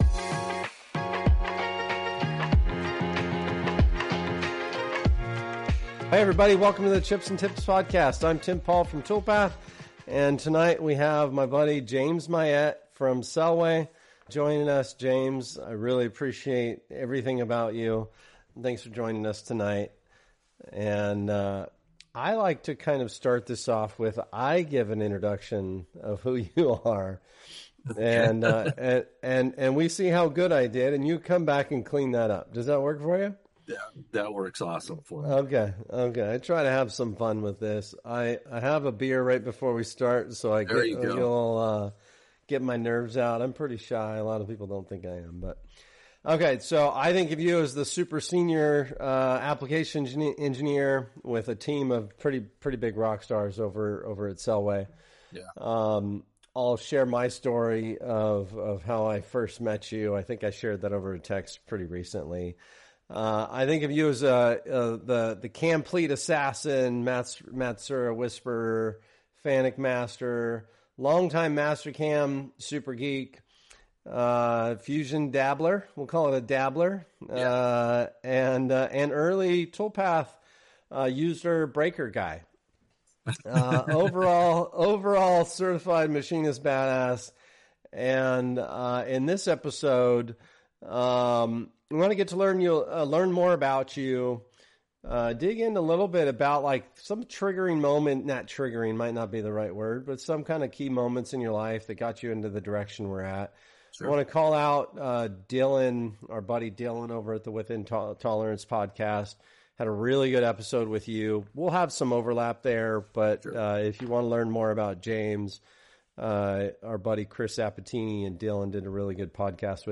They cover the evolution of CAM systems, why associativity changed everything, and how five-axis machining quickly exposes weak processes. The conversation dives into multi-pallet five-axis setups, post-processing and simulation realities, CAMplete, Mastercam, Fusion, and where modern tools help without replacing experience.